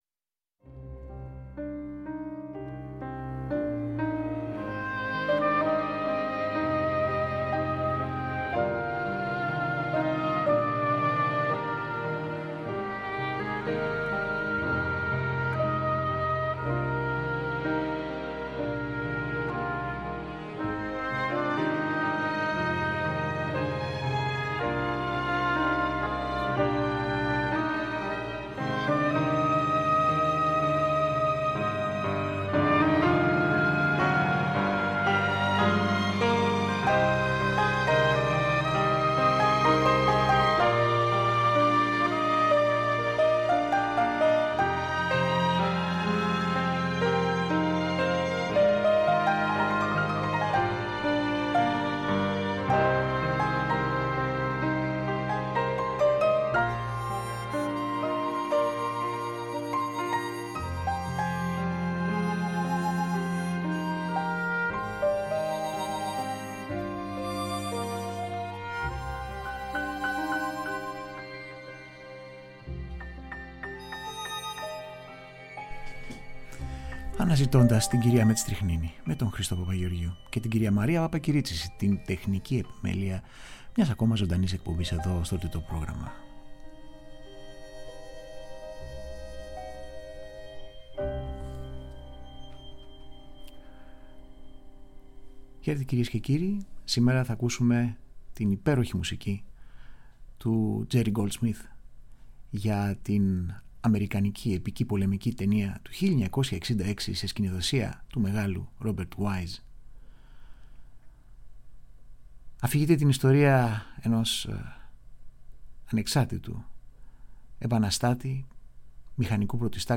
Original Film Score
κινηματογραφικη μουσικη